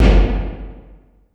59 REV-BD1-L.wav